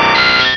Cri de Scarabrute dans Pokémon Rubis et Saphir.